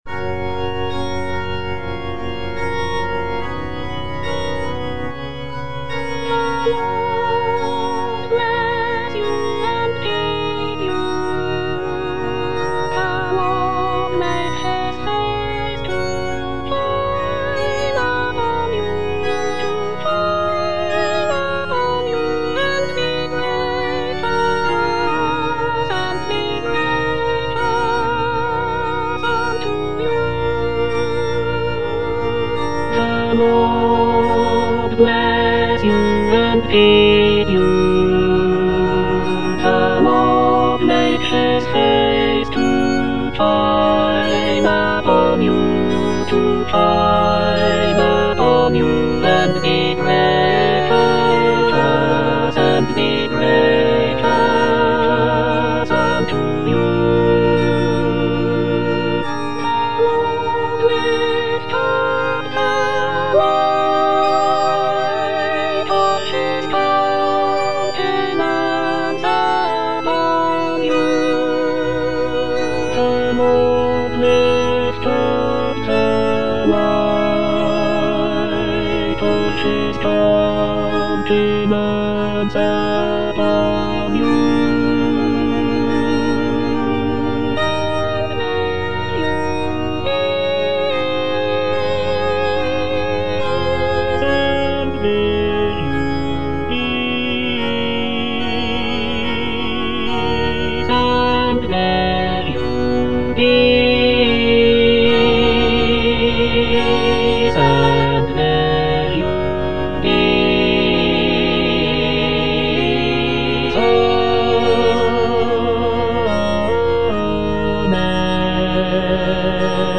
All voices
a choral benediction